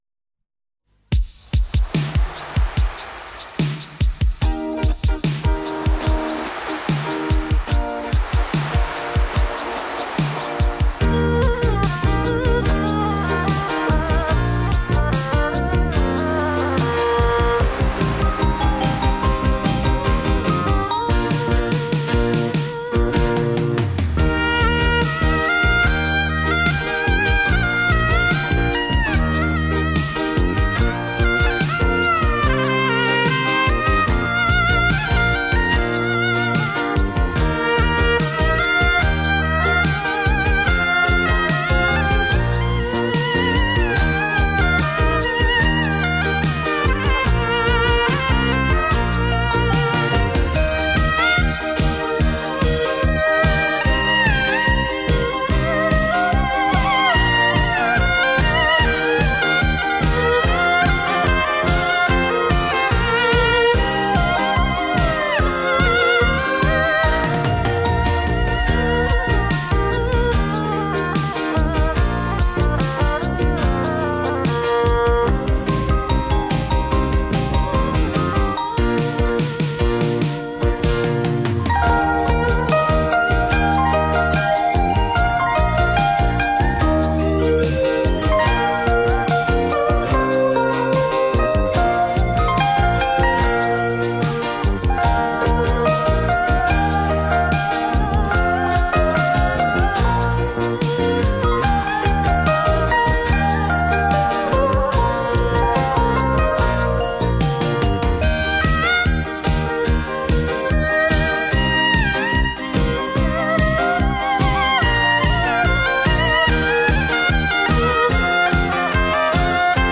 旋律流畅优美； 充满浓浓的民族风韵